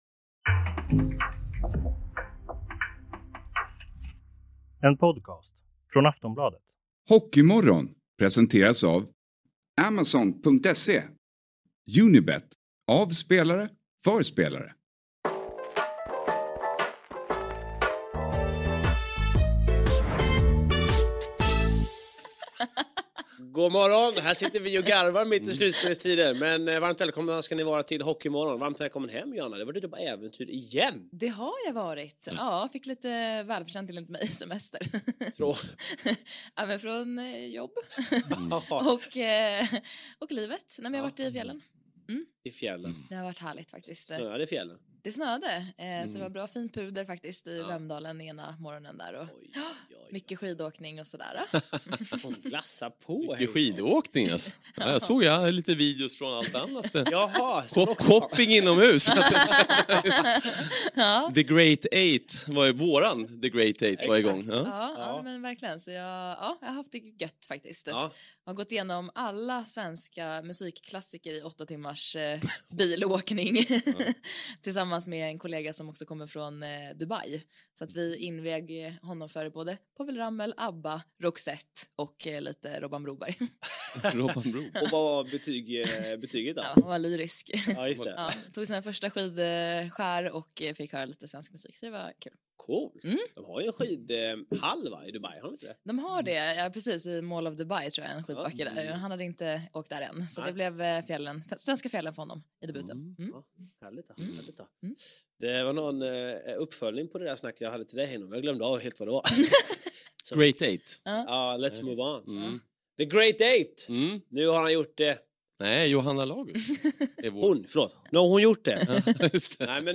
Vi har ännu en domardiskussion och frågar oss om fokuset går till överdrift. Till sist gästas vi av "The Moose", Johan Hedberg, som i helgen presenterades som Leksands nya tränare.